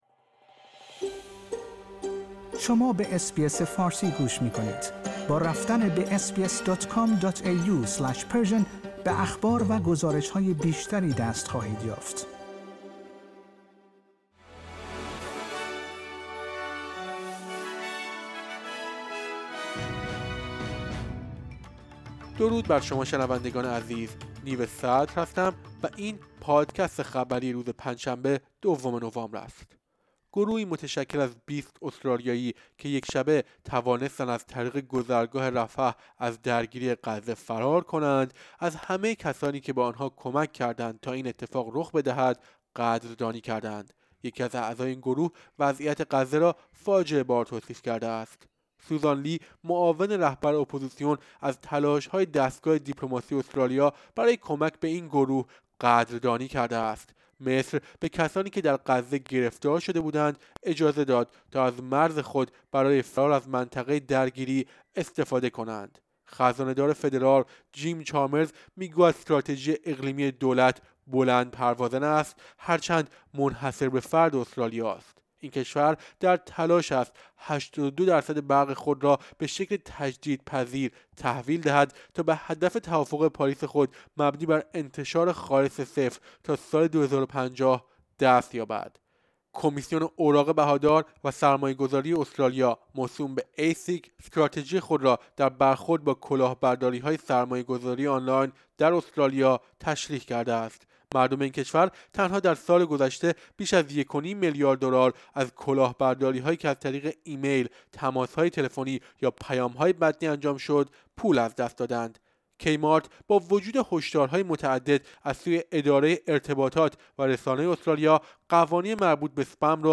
در این پادکست خبری مهمترین اخبار استرالیا و جهان در روز پنجشنبه دوم نوامبر ۲۰۲۳ ارائه شده است.